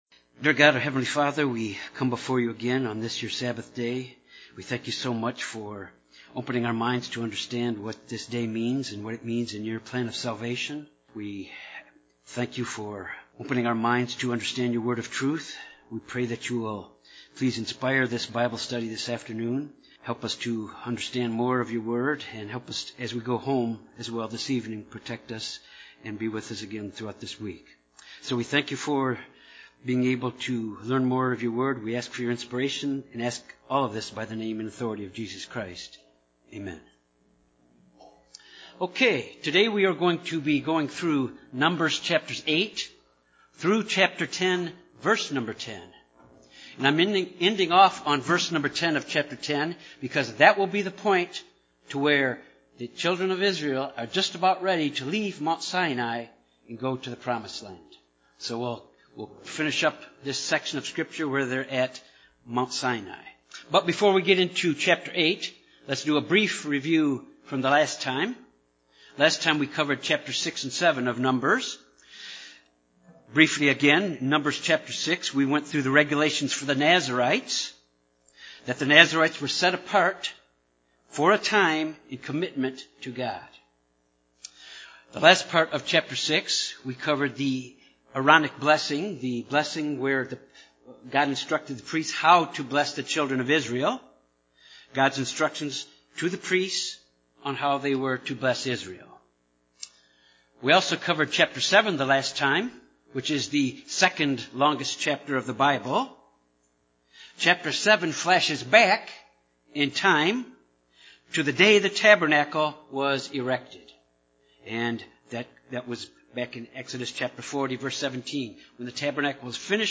This Bible study examines Numbers 8 through verse 10 of chapter 10.
Given in Jonesboro, AR Little Rock, AR Memphis, TN